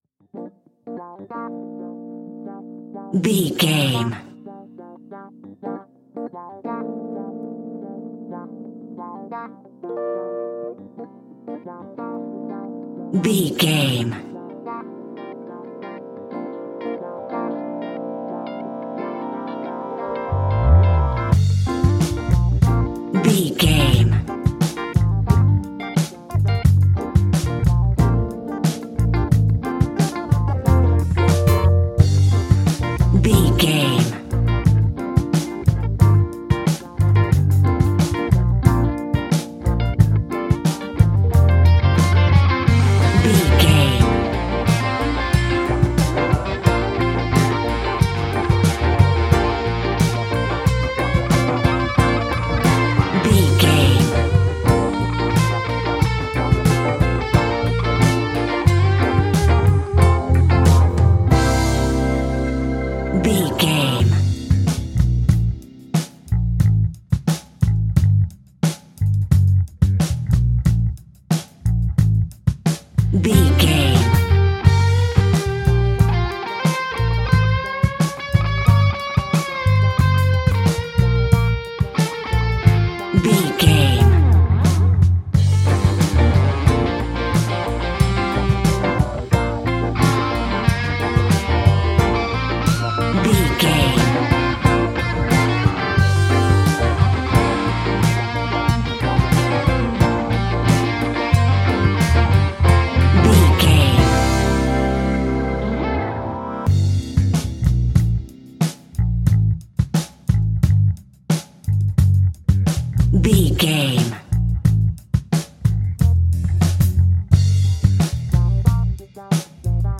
Aeolian/Minor
funky
uplifting
bass guitar
electric guitar
organ
drums
saxophone